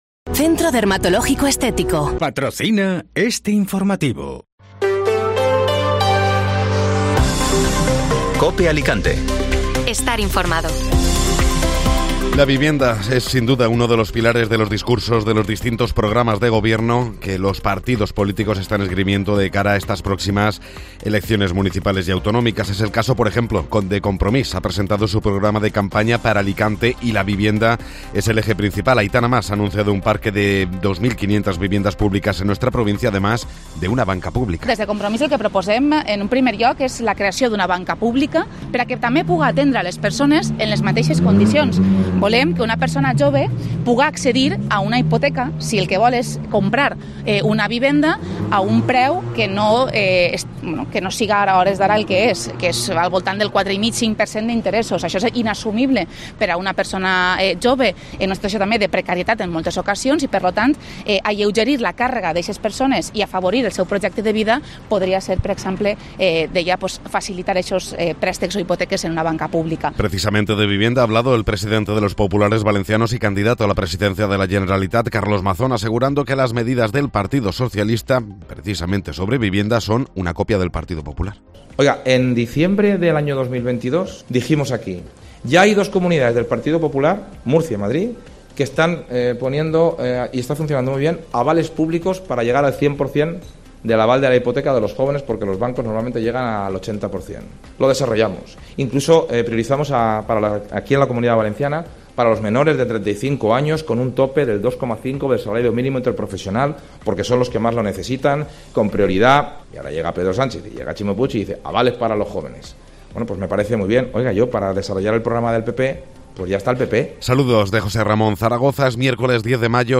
Informativo Matinal (Miércoles 10 de Mayo)